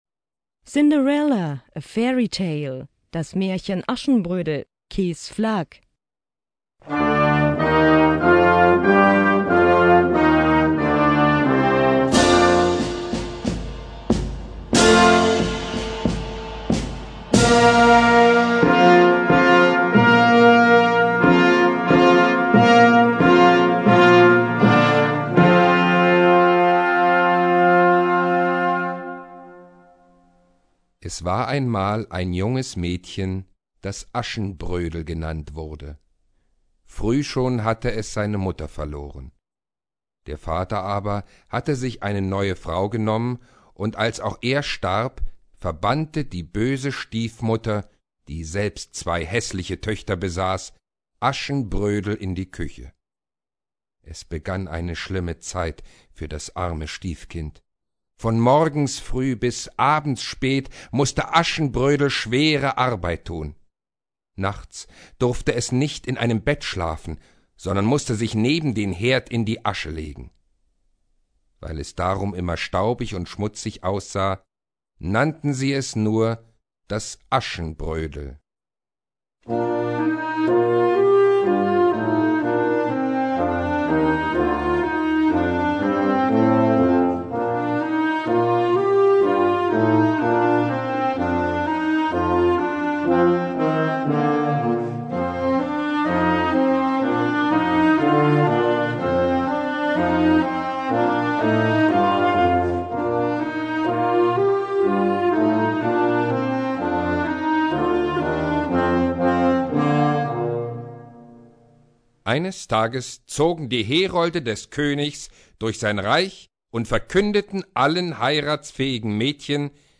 Gattung: Märchen-Variationen für Jugendblasorchester
Besetzung: Blasorchester